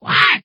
snd_goblindie.ogg